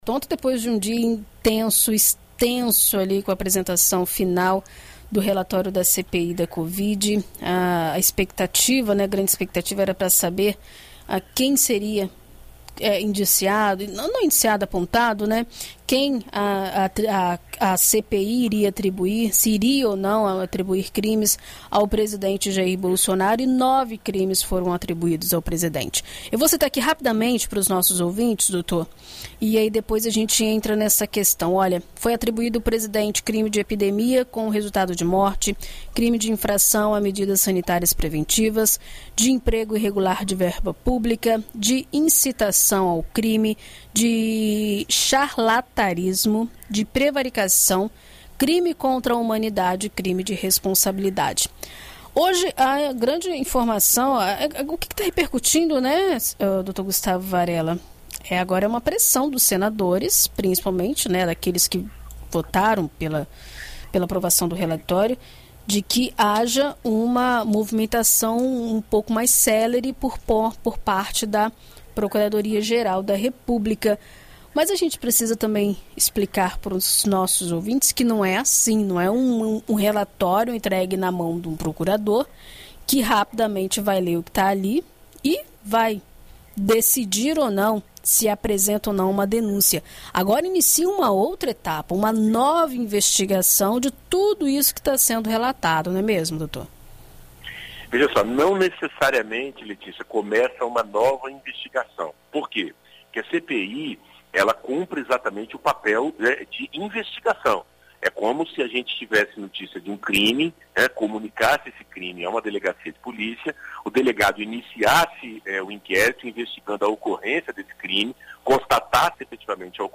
Na coluna Direito para Todos desta quarta-feira (27), na BandNews FM Espírito Santo